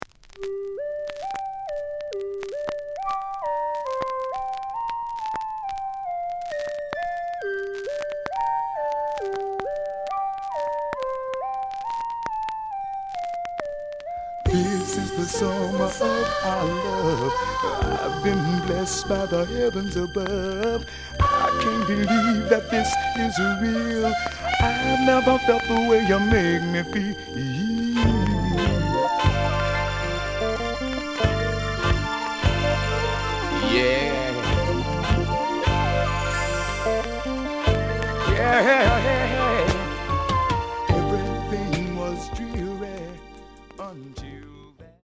The audio sample is recorded from the actual item.
●Genre: Funk, 70's Funk
Slight noise on B side.